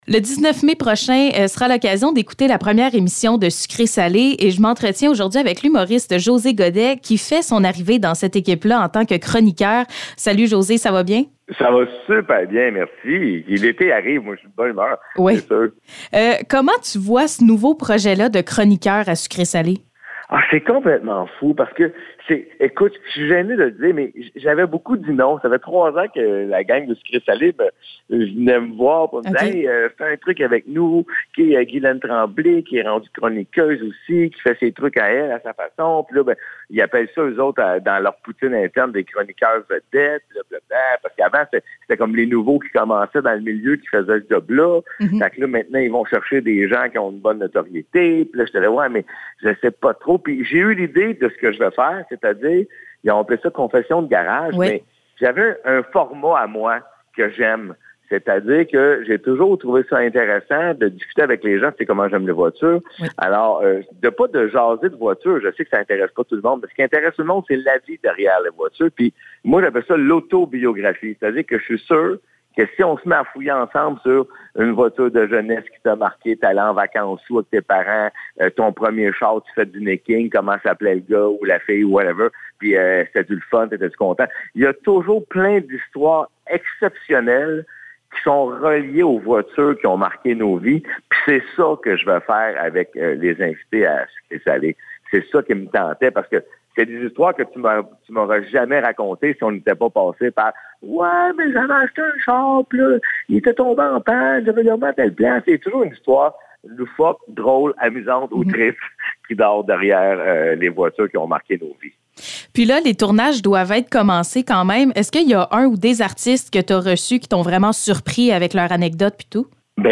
Entrevue José Gaudet
Entrevue avec José Gaudet qui nous parles de son aventure qui commence comme chroniqueur à Sucré Salé et de son spectacle.
entrevue-Jose-Gaudet-VERSION-FINALE.mp3